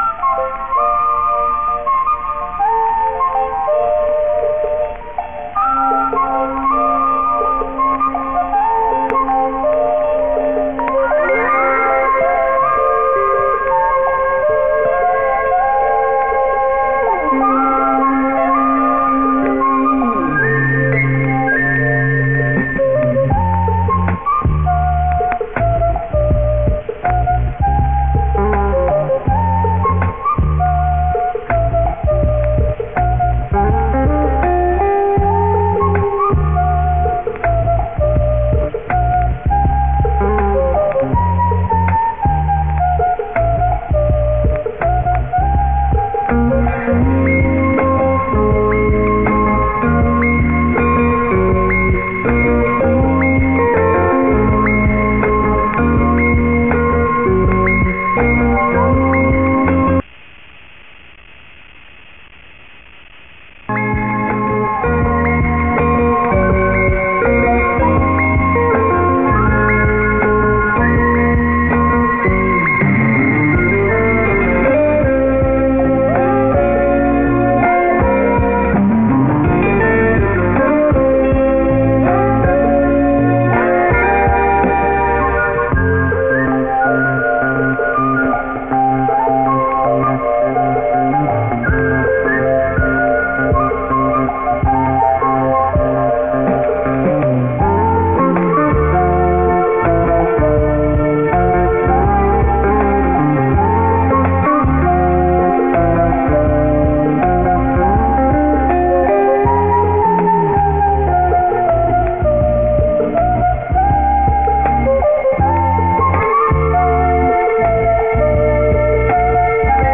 found on geocities with no information, dont recognize it and a meagre search effort turned up nothing so its untitled
three separate 6khz files compiled into one with added noise gaps because it wasnt seamless and it feels better this way